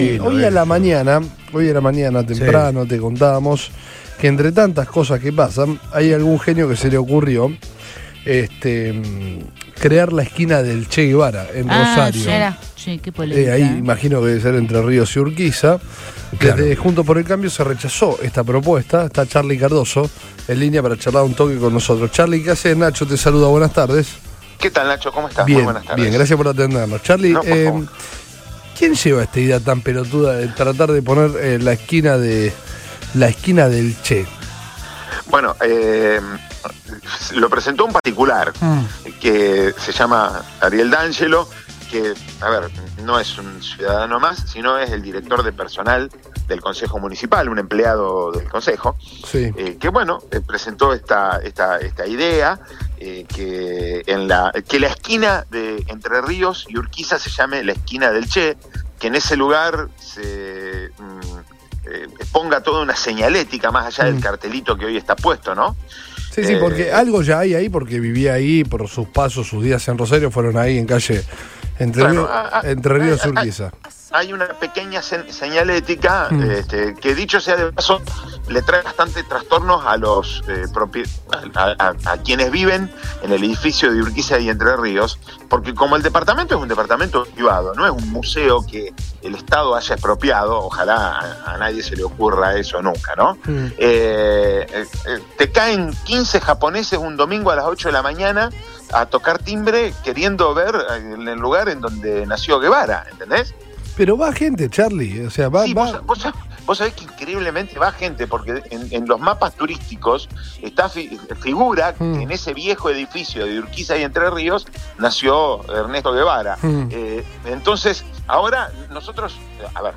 En diálogo con Todo Pasa de Radio Boing, el concejal Carlos Cardozo manifestó su total reprobación y repudio por el intento de sumar otro homenaje a la figura de Ernesto Che Guevara.